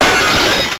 new glass noises
glass_break.ogg